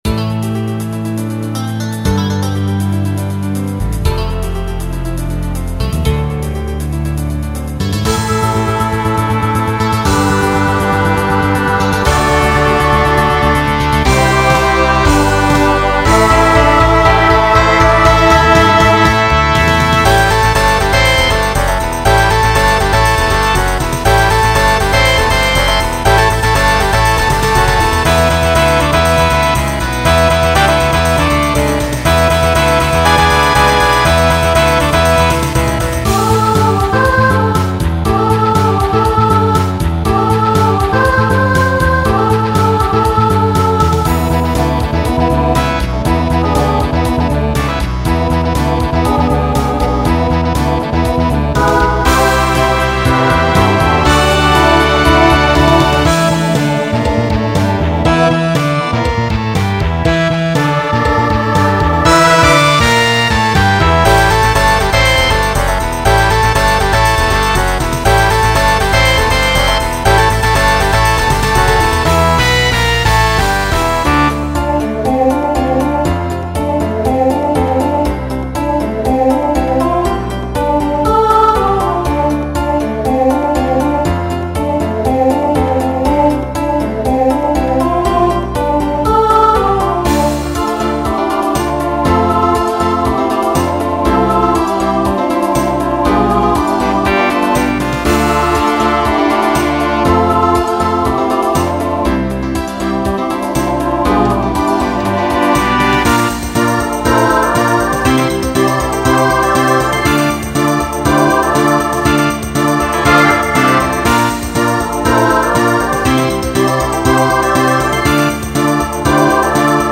Genre Broadway/Film , Rock Instrumental combo
Voicing SSA